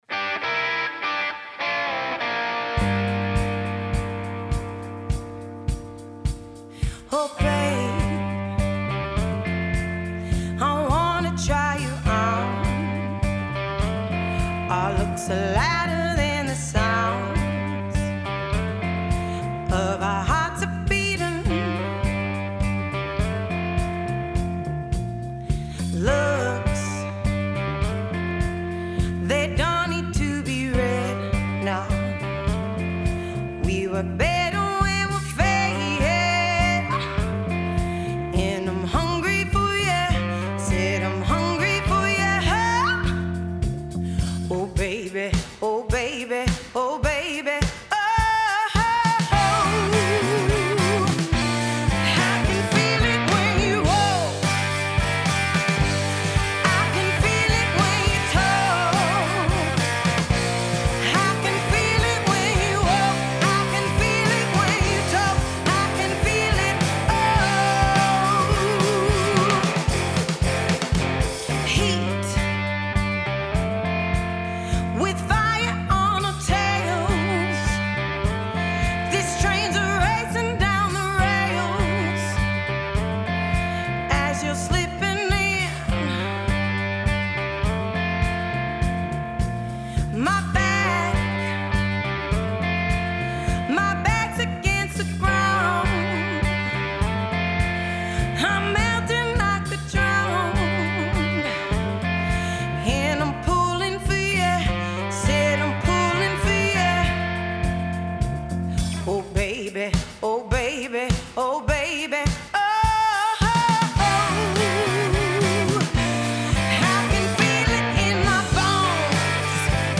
Nice mix and use of delays!